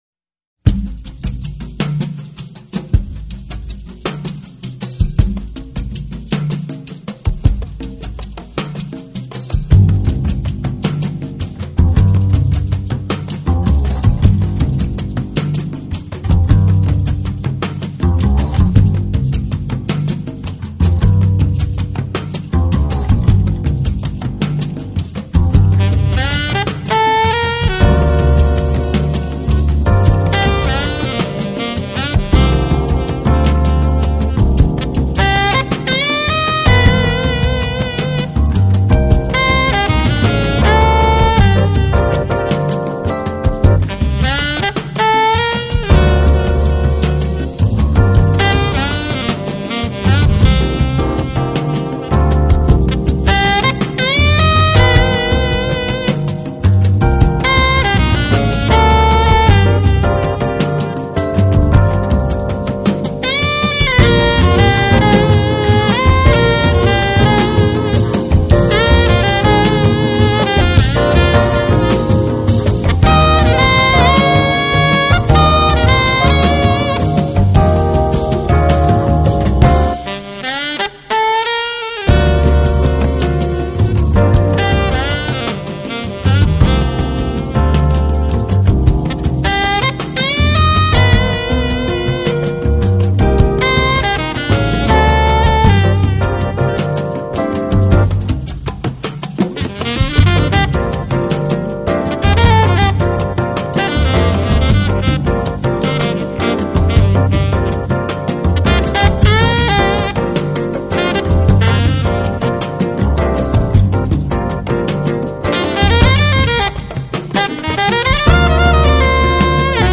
【爵士萨克斯】
Flower弹奏钢琴。